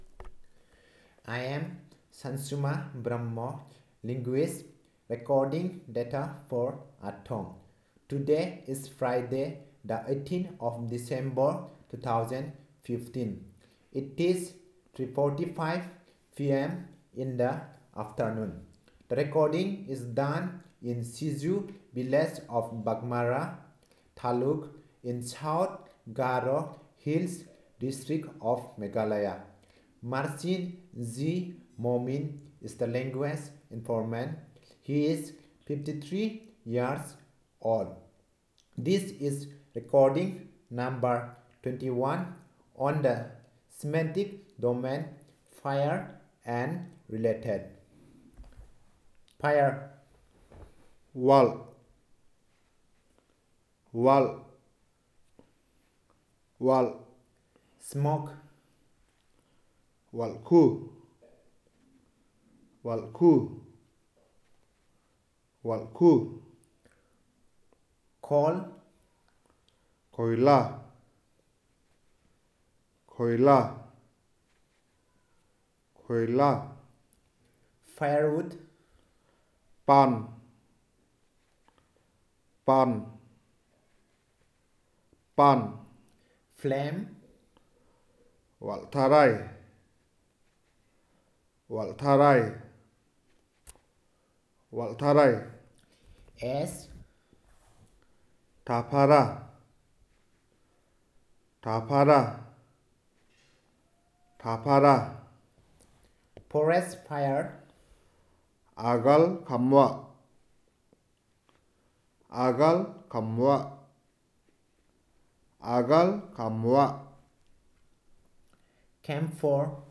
Elicitation of words about fire and related